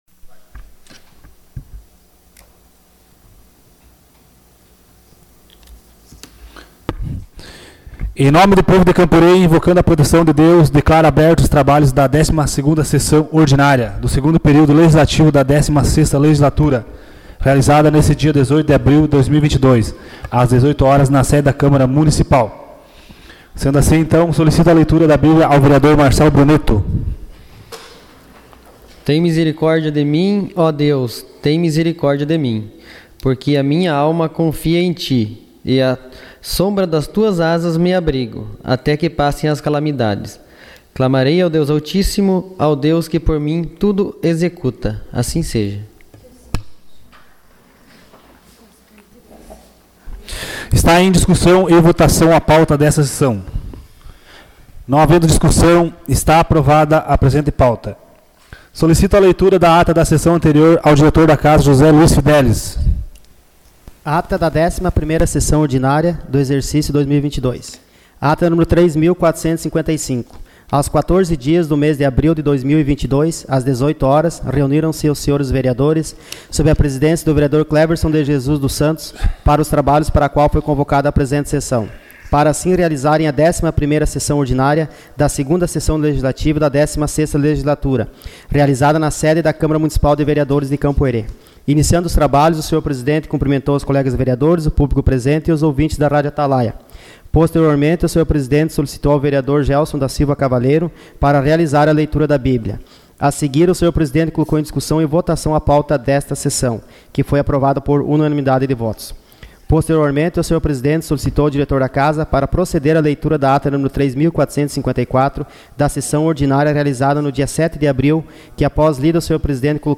Sessão Ordinária dia 18 de abril de 2022